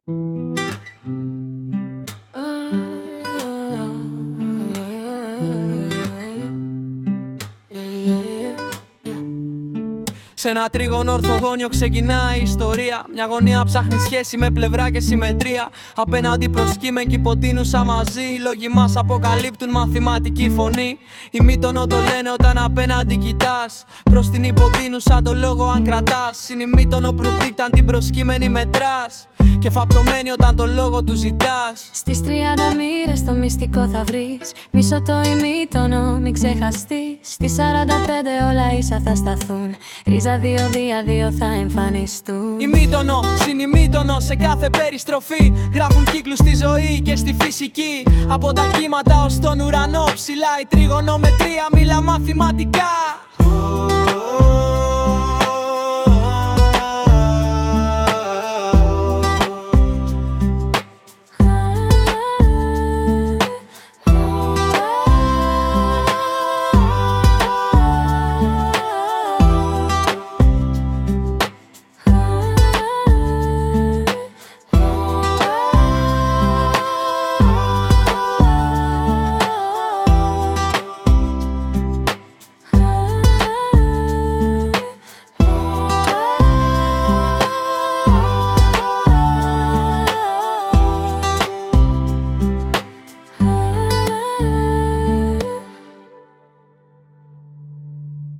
Δημιουργήθηκε για να κάνει την Τριγωνομετρία λίγο πιο διασκεδαστική! Οι στίχοι γράφτηκαν με τη βοήθεια Τεχνητής Νοημοσύνης, ενώ η μουσική σύνθεση και η εκτέλεση πραγματοποιήθηκαν με τη βοήθεια της πλατφόρμας Suno.
trigonometrysong.mp3